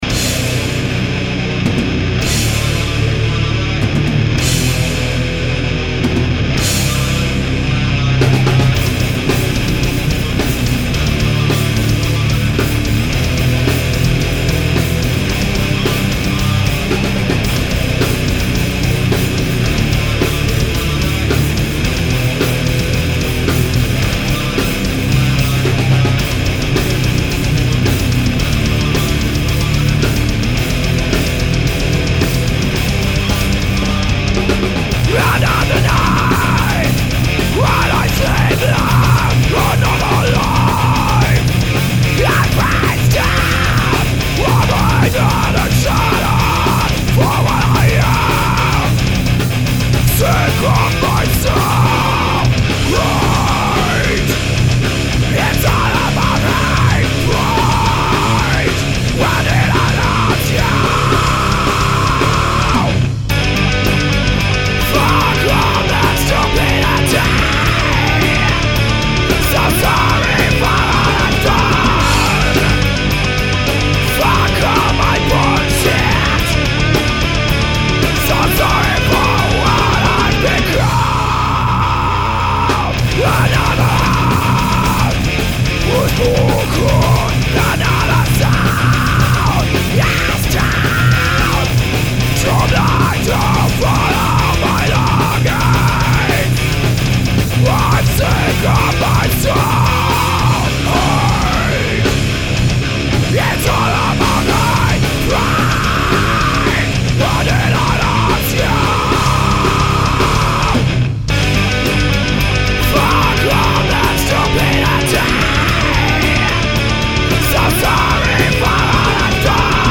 Wir haben gestern im Proberaum mit relativ wenig Aufwand (BD getriggert, 2x Studioprojects Großmembranen, 3x Thoman billig Tom Mirkos davon 1 an der Snare) ein paar alte Riffs verwertet.